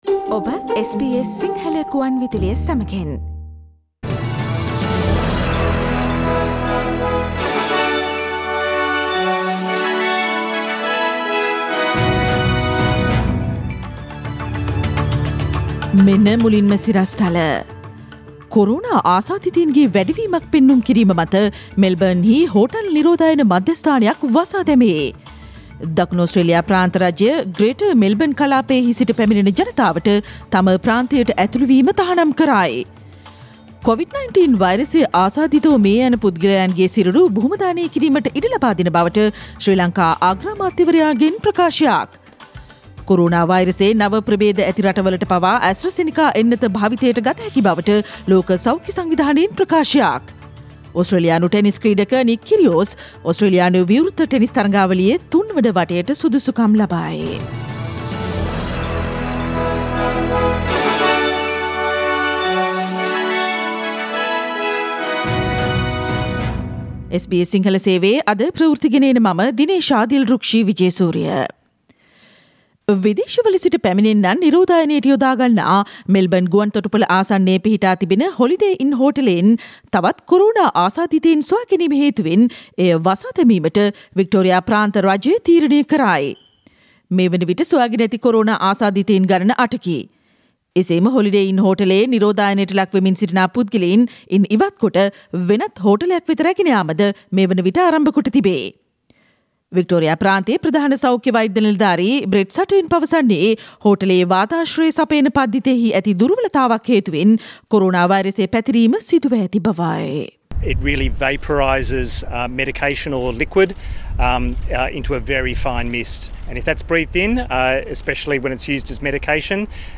SBS Sinhala radio news on 11 February 2021.